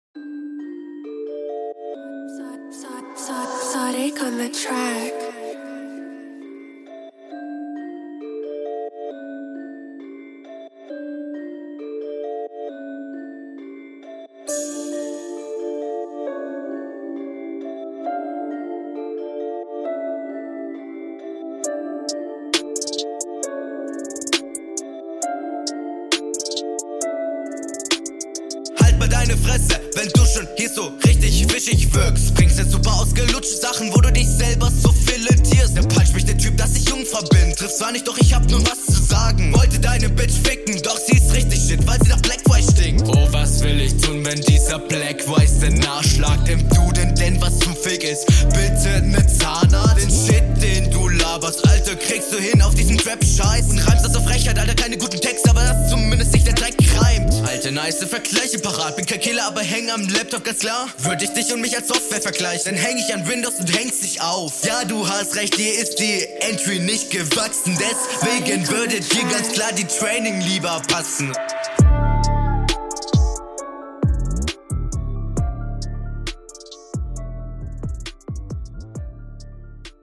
Sound auch sehr gut. Flow finde ich um einiges besser als in der HR1.